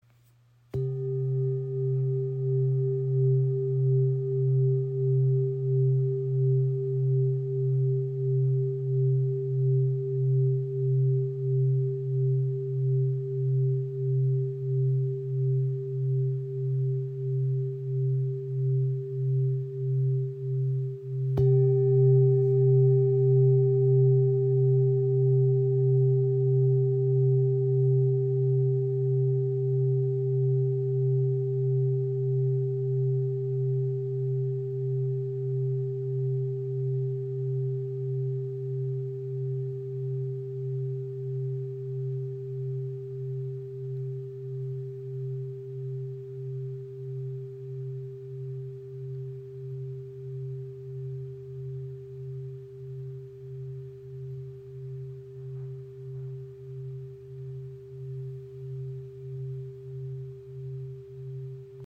Klangschale | Lebensblume | ø 25.5 cm | Ton ~ C | Synodischer Mond (132,00 Hz)
Handgefertigte Klangschale aus Kathmandu
• Icon Inklusive passendem rotem Filzschlägel
• Icon Tonhöhe C | Planetenton Synodischer Mond (132,00 Hz) | 1940 g.
In der Klangarbeit wirkt er besonders nährend, weich und verbindend.